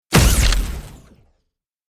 Download Free Sci-Fi Robotic Sound Effects | Gfx Sounds
Robot-punch-impact.mp3